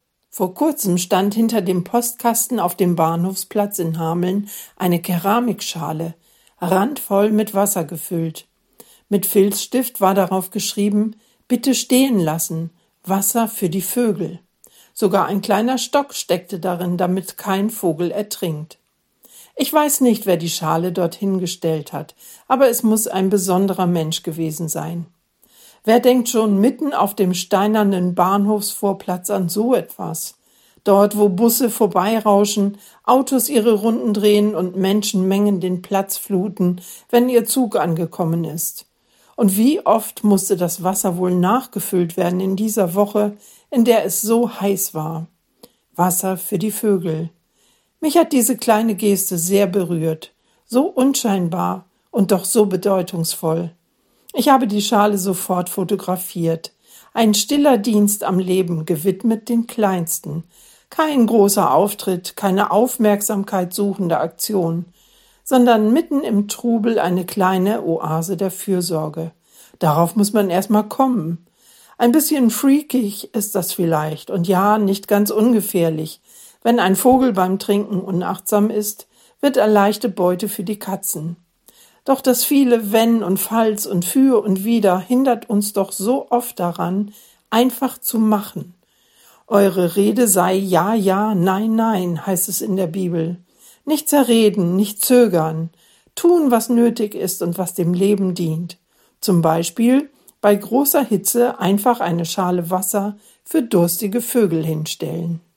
Radioandacht vom 23. Juli